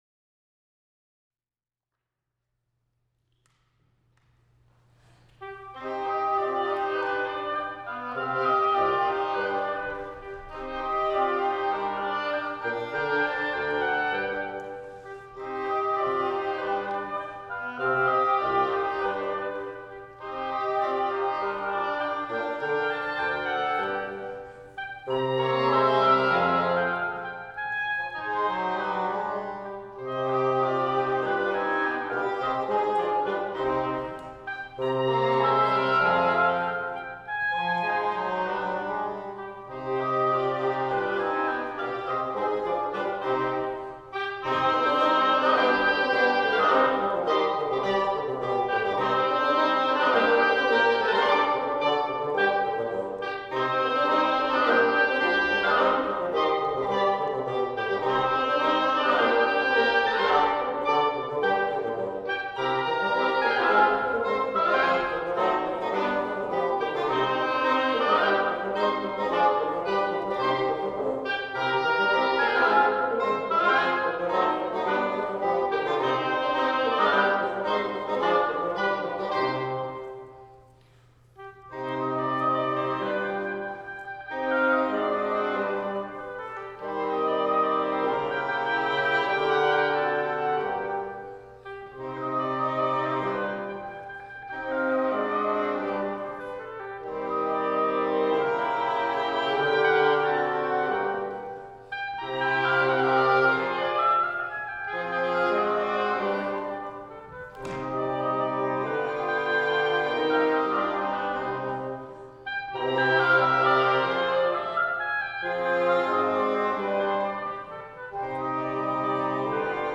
So you can imagine my excitement to learn that Mozart himself arranged the Serenade for a viola-quintet (K406), which translates really well back into a wind piece – for two oboes, two English horns, and bassoon! We played two movements from this past January in the Brockton Symphony chamber concert.
Oboe
English Horn